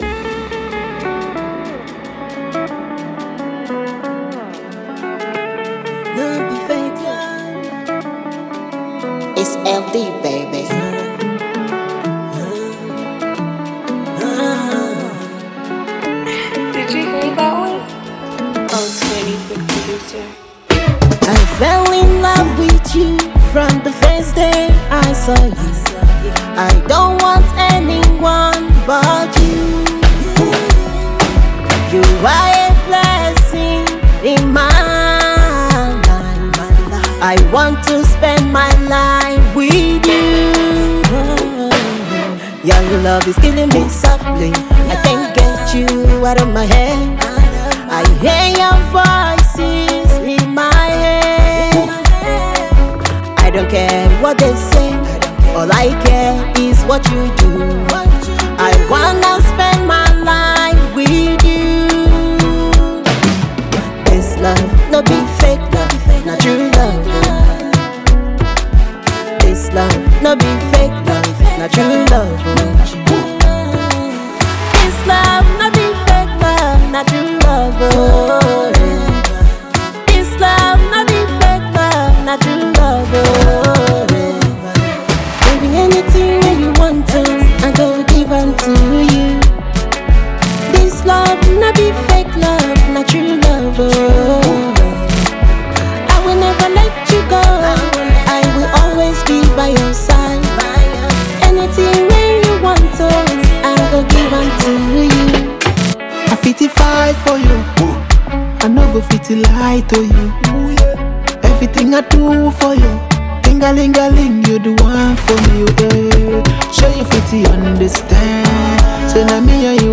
sensational contemporary singer
a love song